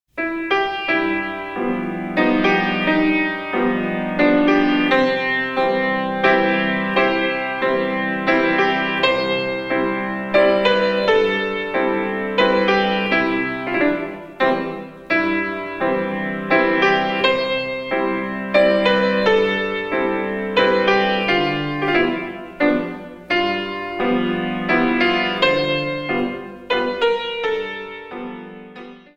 In 3
128 Counts